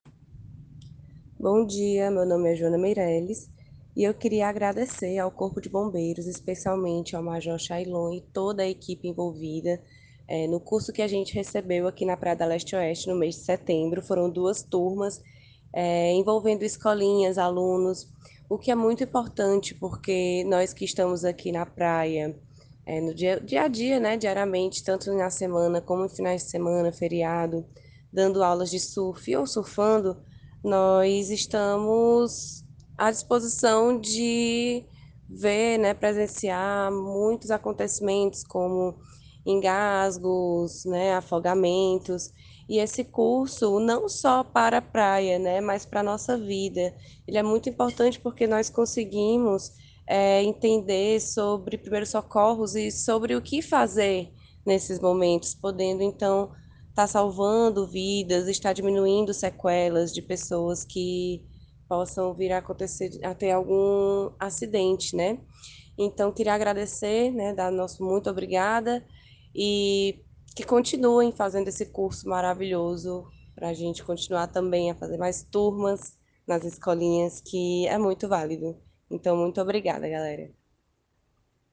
Depoimento